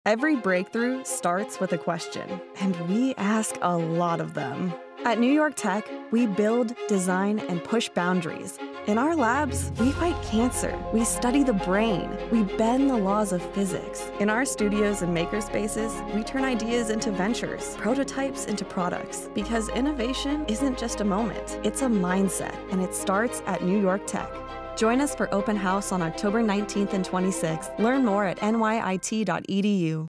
radio-ad-2025.mp3